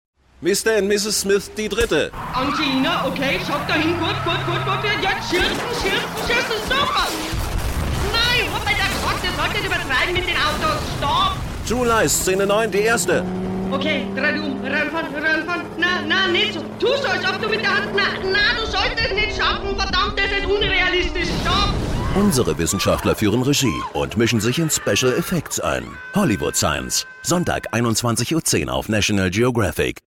deutsche Sprecherin mit warmer, sinnlicher, sehr wandlungsfähiger Stimme, Stimmlage mittel / tief,
Sprechprobe: Sonstiges (Muttersprache):